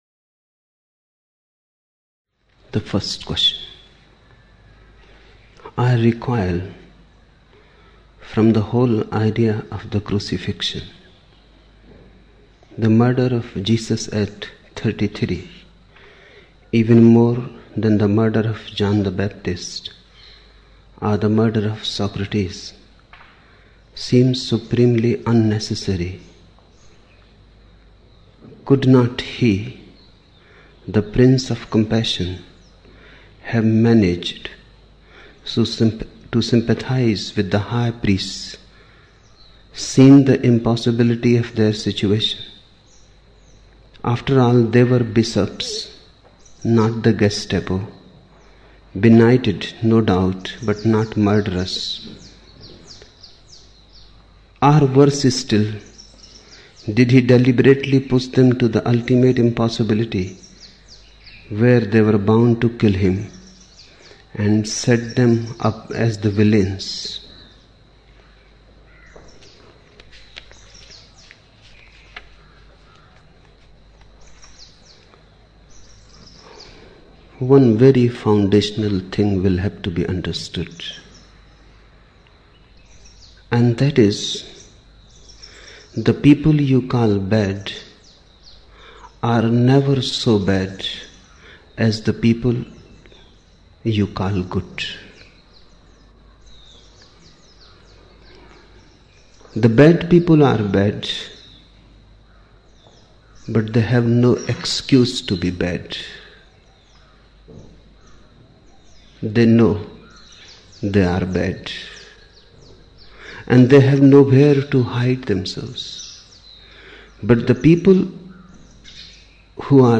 3 November 1975 morning in Buddha Hall, Poona, India